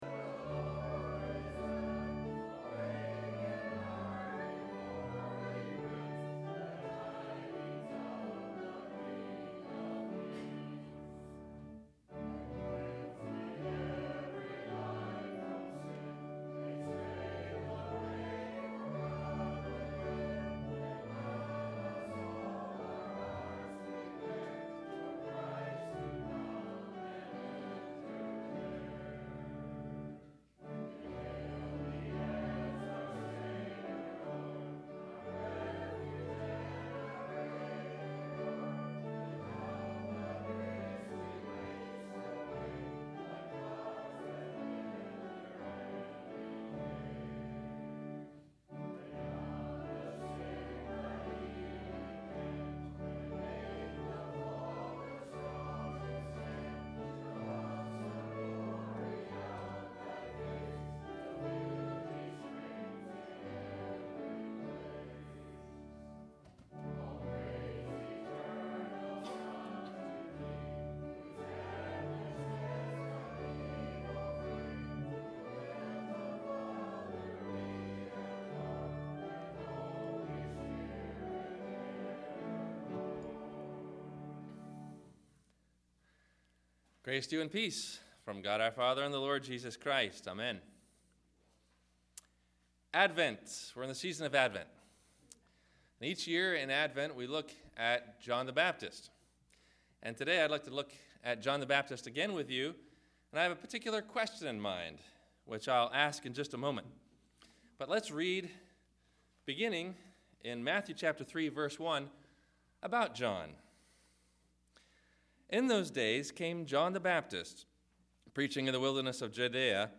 Should We Still Preach Fire and Brimstone ? – Sermon – December 05 2010